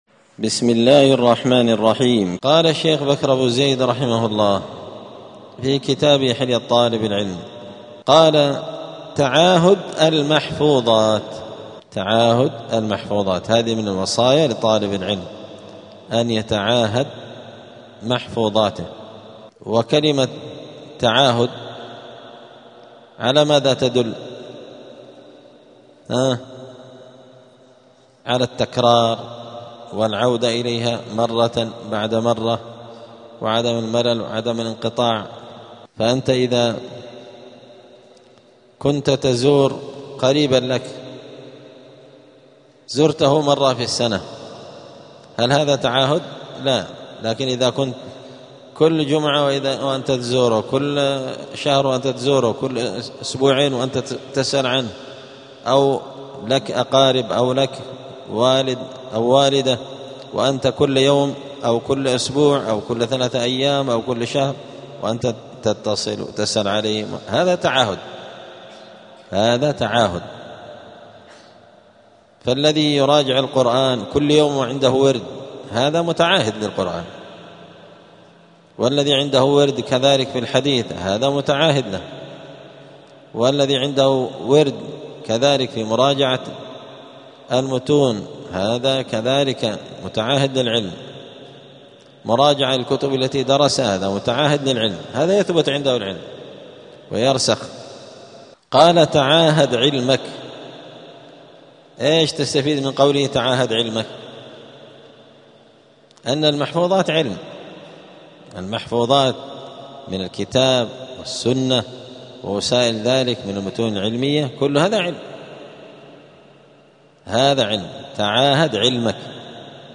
السبت 22 جمادى الآخرة 1447 هــــ | الدروس، حلية طالب العلم، دروس الآداب | شارك بتعليقك | 7 المشاهدات